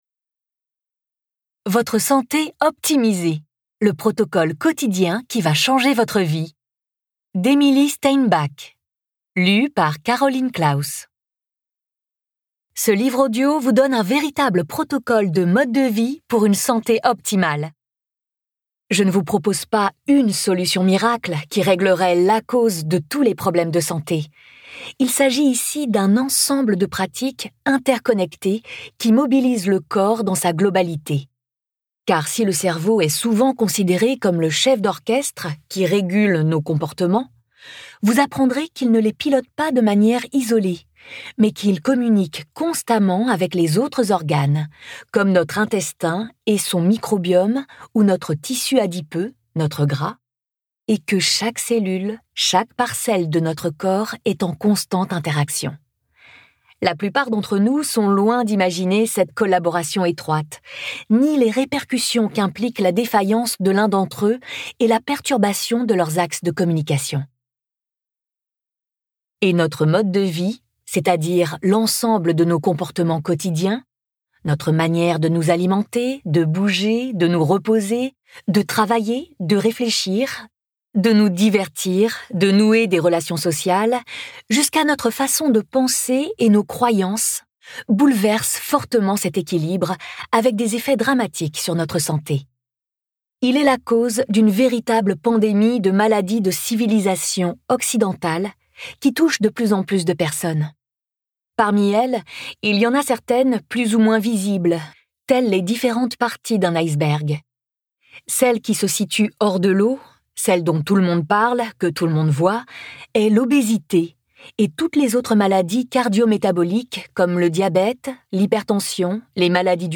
Votre santé optimisée Le protocole quotidien qui va changer votre vie Audiolib
Interprétation humaine Durée : 09H52 23 , 95 € Ce livre est accessible aux handicaps Voir les informations d'accessibilité